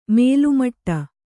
♪ mēlu maṭṭa